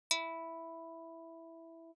• 弦から空気に伝播する音